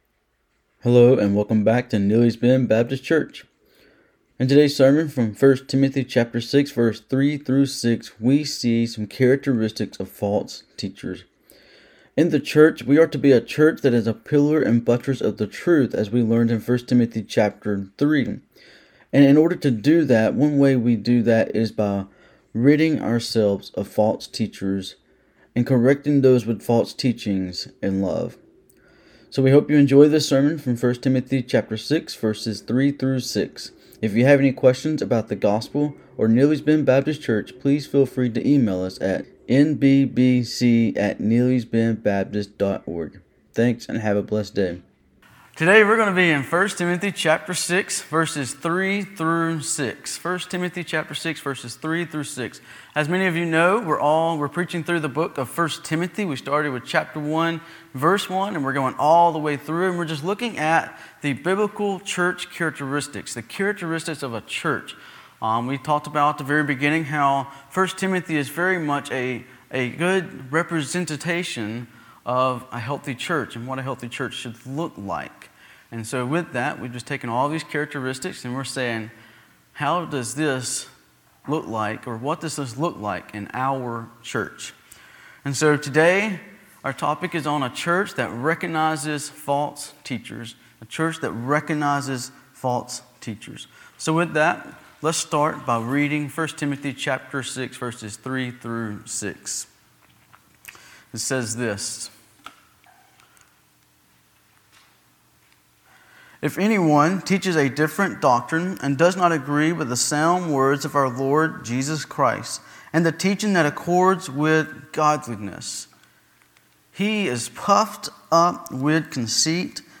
In this sermon from 1 Timothy 6:3-6 we see three characteristics of false teachers. False teachers teach a different doctrine. False teachers create disunity in the church.